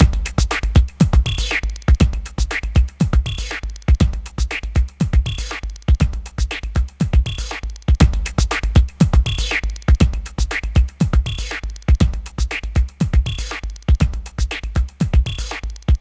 Downtempo 13.wav